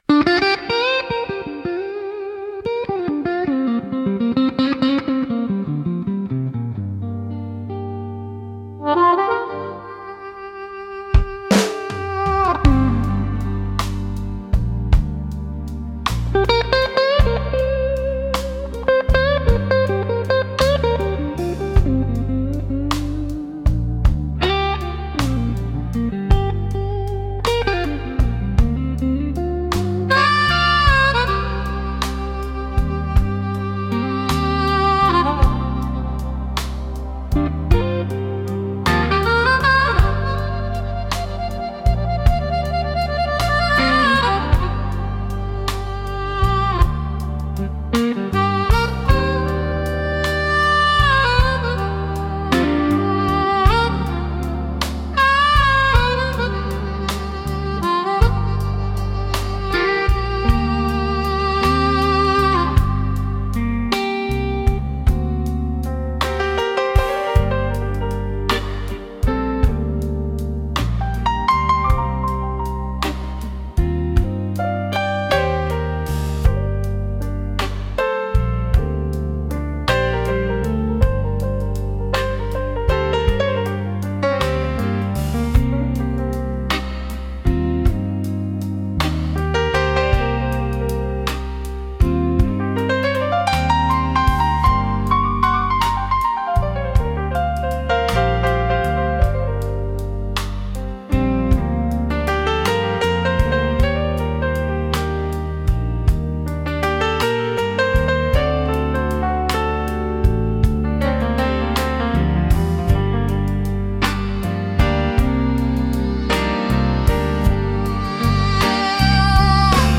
ギターやハーモニカによる哀愁のあるメロディとリズムが、深い感情表現を生み出します。